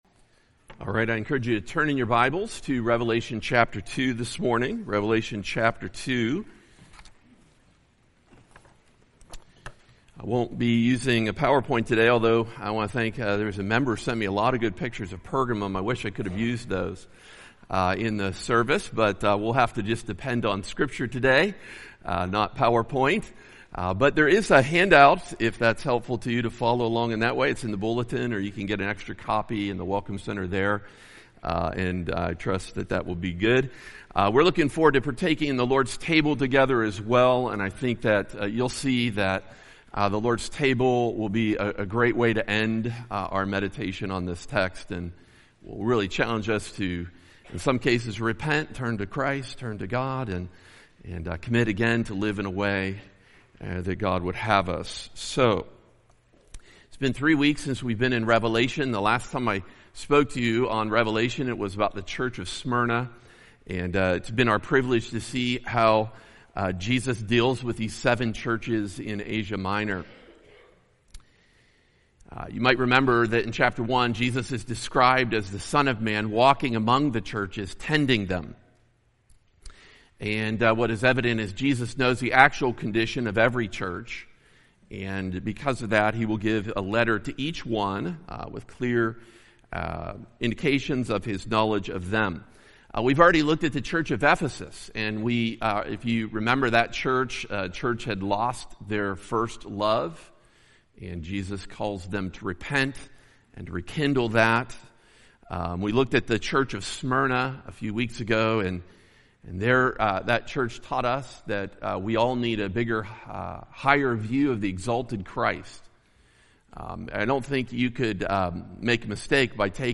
preaches on Revelation 2:12-17.